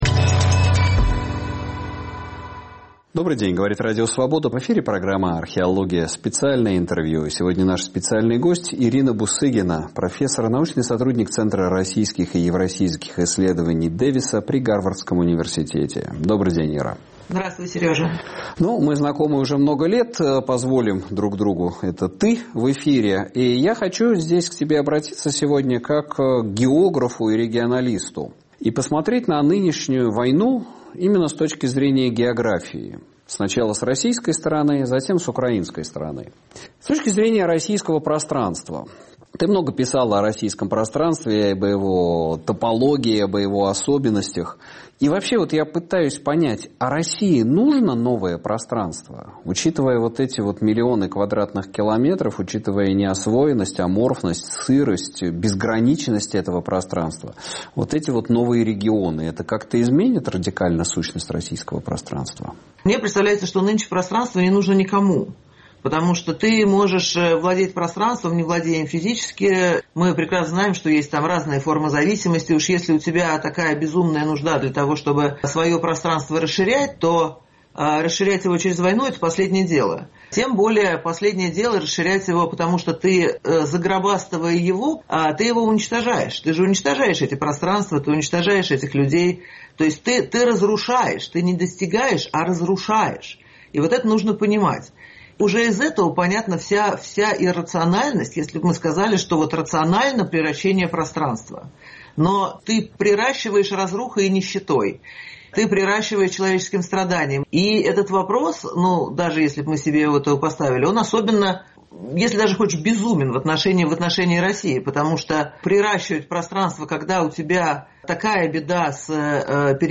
Археология. Интервью